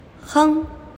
怎么读
hēng
hng